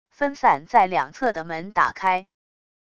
分散在两侧的门打开wav音频